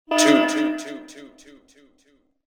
SelfDestructTwo.wav